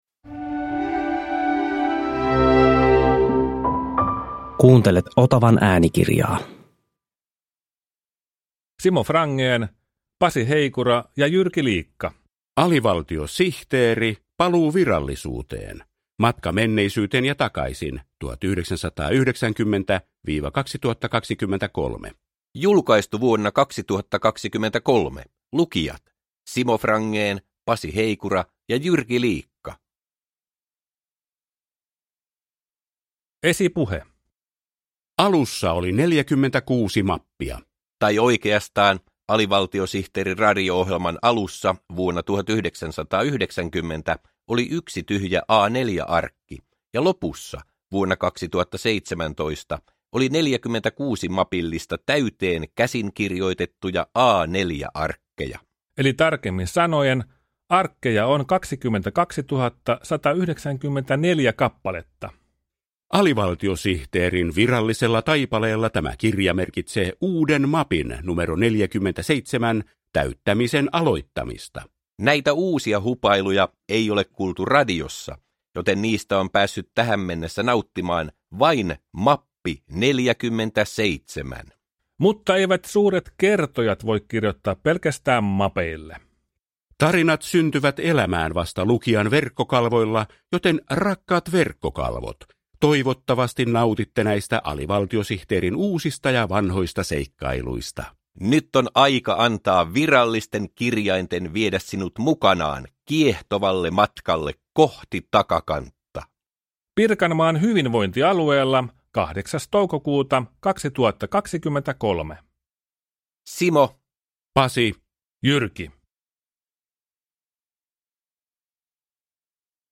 Alivaltiosihteeri - Paluu virallisuuteen – Ljudbok – Laddas ner